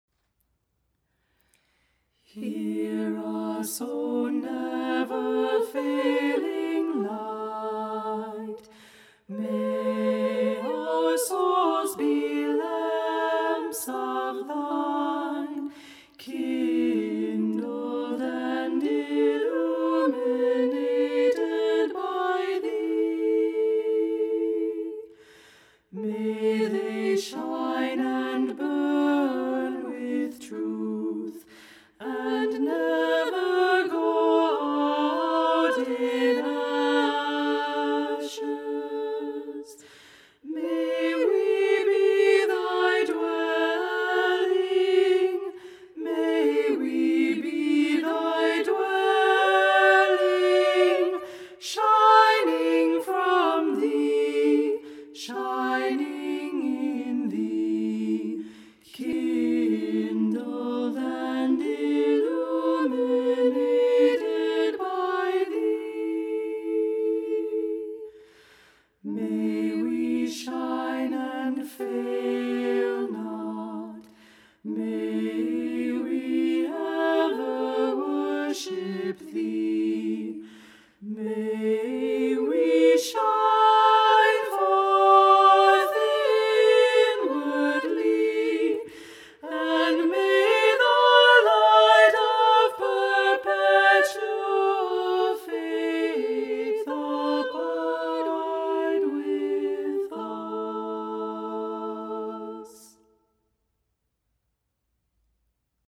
Flexible voicing: 2-4 part choir a cappella